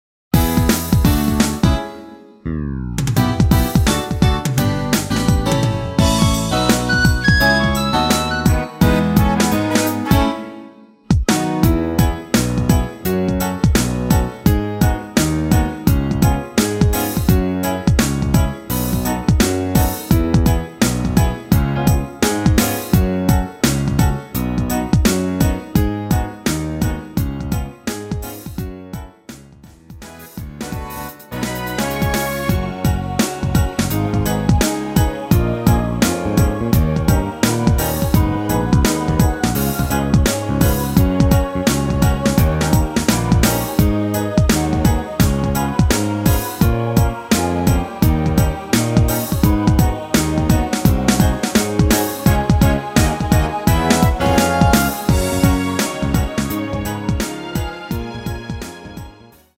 대부분의 남성분이 부르실수 있는 키로 제작 하였습니다.
앞부분30초, 뒷부분30초씩 편집해서 올려 드리고 있습니다.
중간에 음이 끈어지고 다시 나오는 이유는